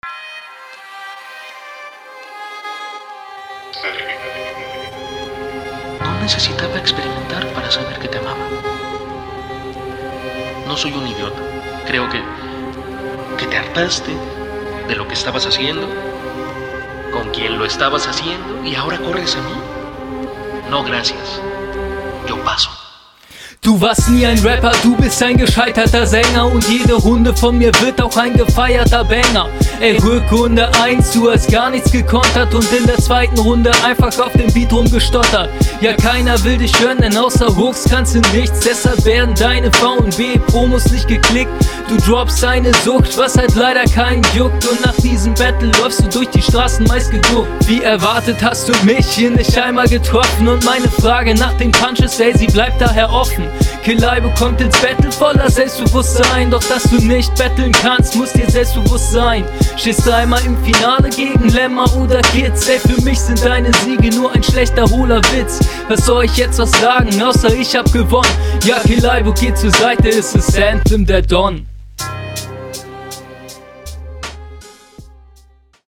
Es geht nach vorne, das gefällt mir.